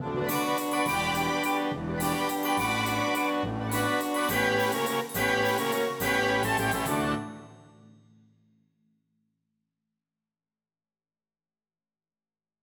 모리스 라벨의 1920년 발레 ''라 발스''는 19세기 말의 빈 왈츠를 미묘하게 반영하며,[21] 가온음 첨가 6화음을 포함하고 있다.